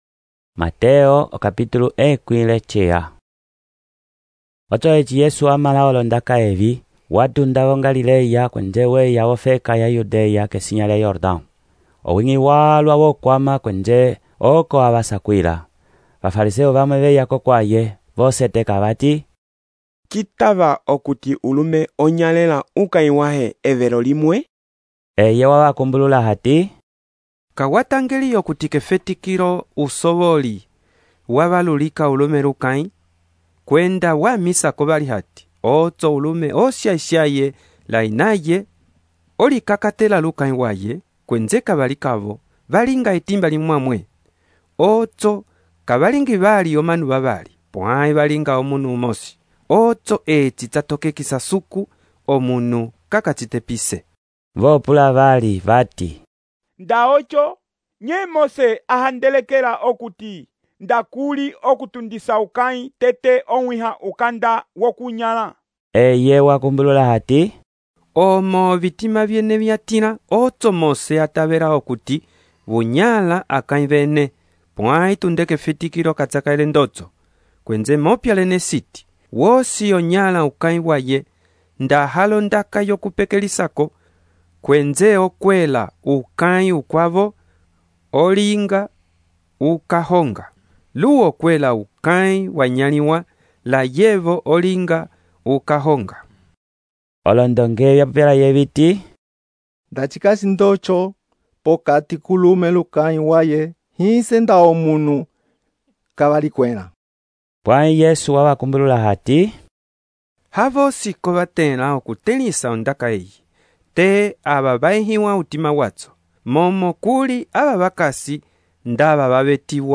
texto e narração , Mateus, capítulo 19